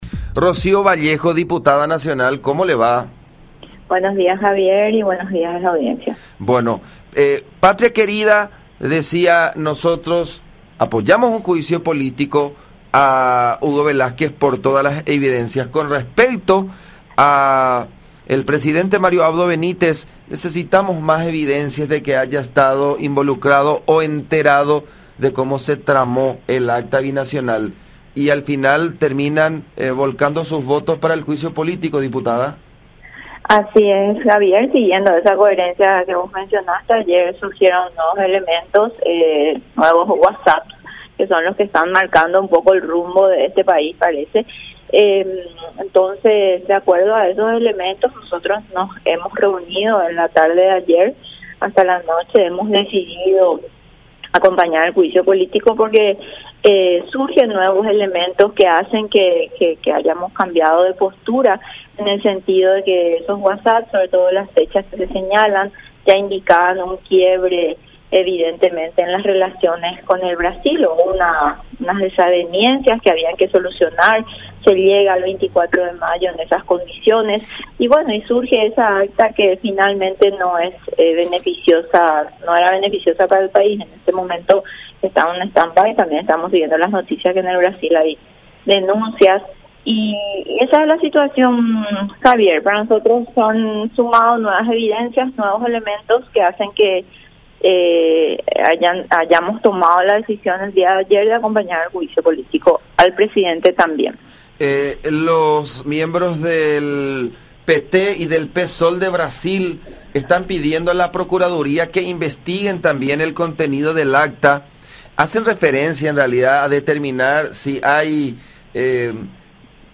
04-Rocío-Vallejo-Diputado-Nacional.mp3